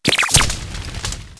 charcadet_ambient.ogg